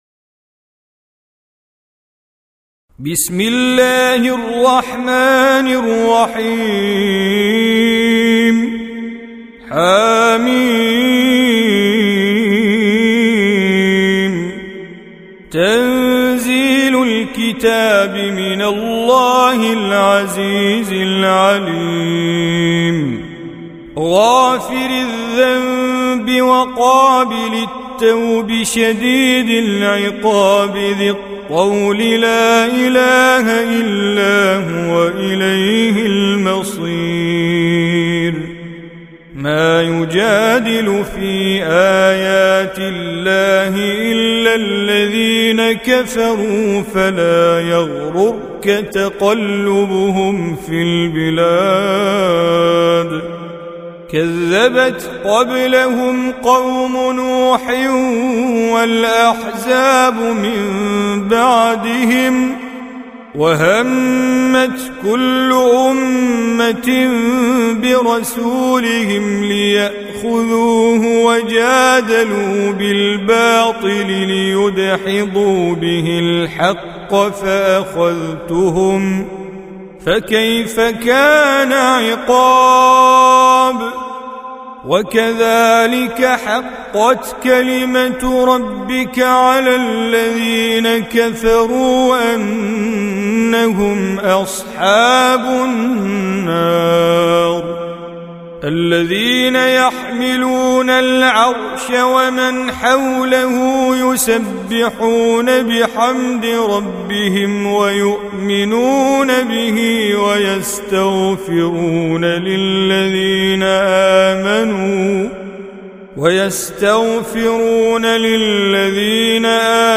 Surah Repeating تكرار السورة Download Surah حمّل السورة Reciting Mujawwadah Audio for 40. Surah Gh�fir سورة غافر N.B *Surah Includes Al-Basmalah Reciters Sequents تتابع التلاوات Reciters Repeats تكرار التلاوات